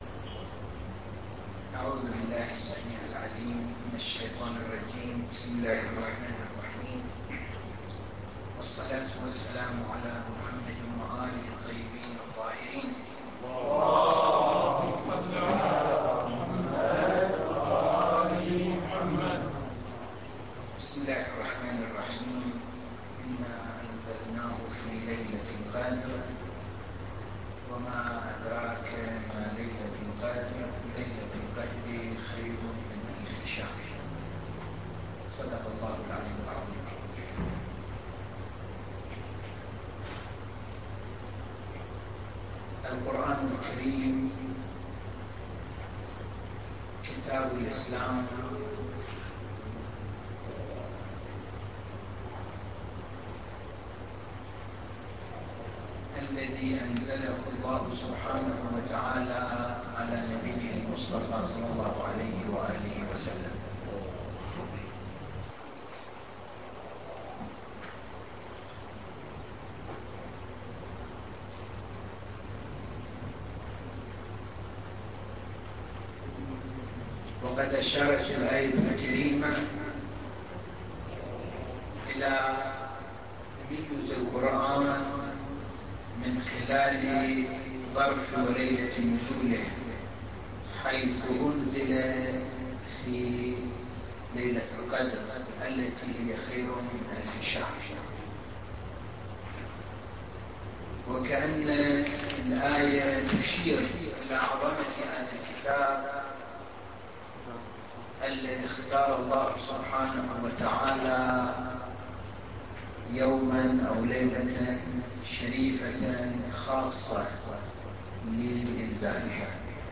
محاضرة رقم 1 - لحفظ الملف في مجلد خاص اضغط بالزر الأيمن هنا ثم اختر (حفظ الهدف باسم - Save Target As) واختر المكان المناسب